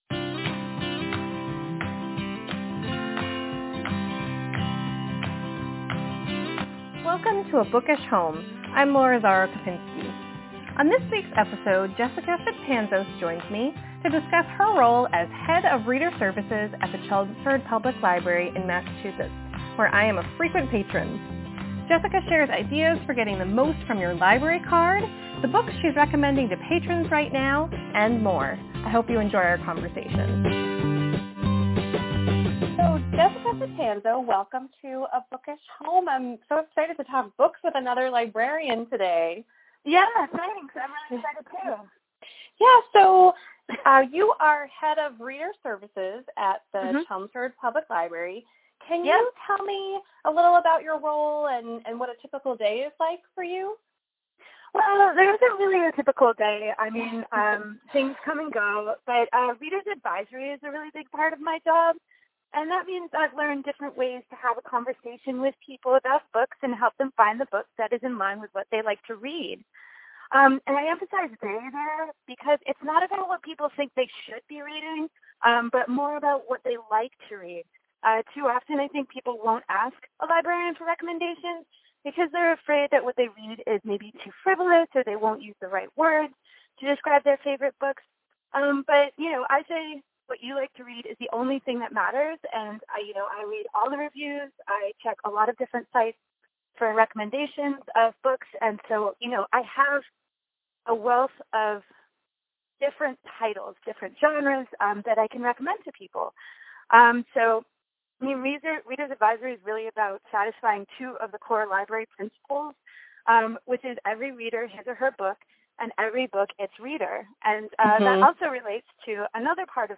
chelmsfordlibraryinterview.mp3